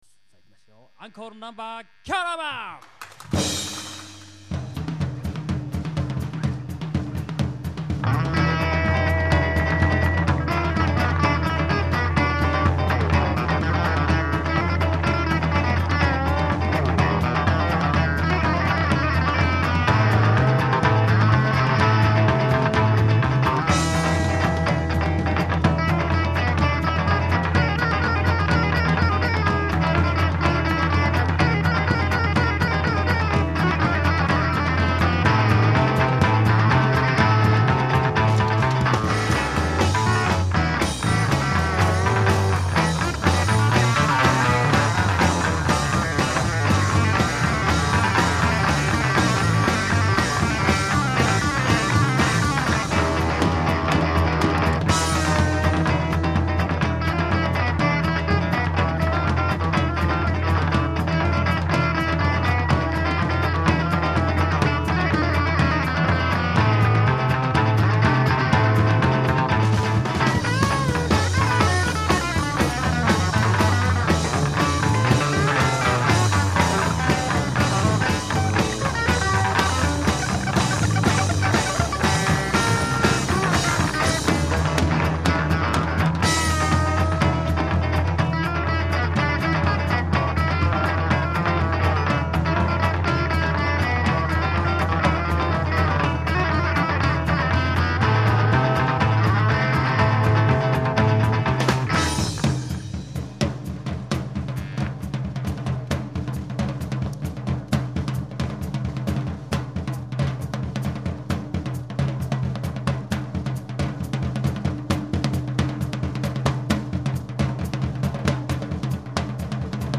Reproducing the Summer of 1965 Japan concerts.